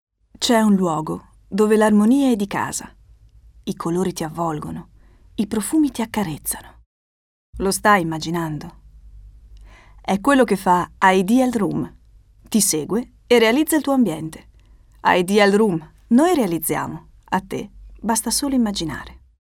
Voce per documentari, spot, voiceover, audioguide, audiolibri
Sprechprobe: Werbung (Muttersprache):